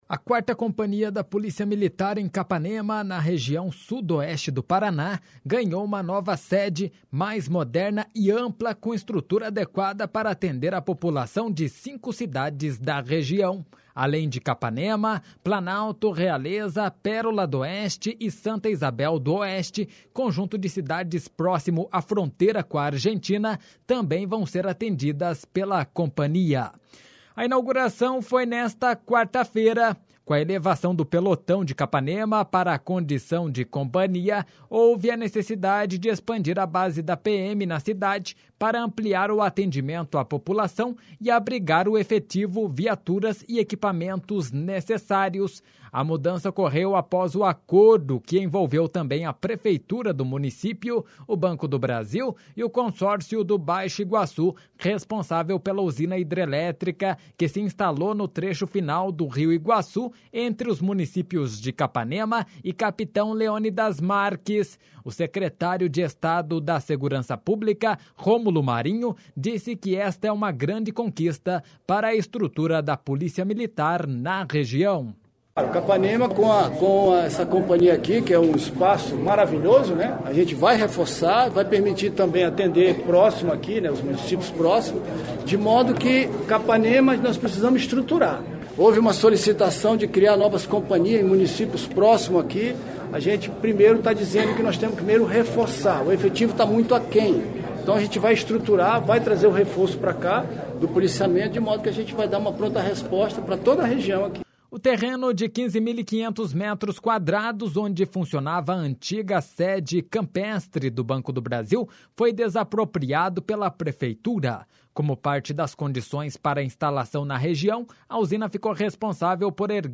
O secretário de Estado da Segurança Pública, Rômulo Marinho, disse que esta é uma grande conquista para a estrutura da Polícia Militar na região.// SONORA RÔMULO MARINHO.//O terreno de 15.500 metros quadrados, onde funcionava a antiga sede campestre do Banco do Brasil, foi desapropriado pela prefeitura.
O prefeito de Capanema, Américo Belle, destacou a boa relação com o governo estadual.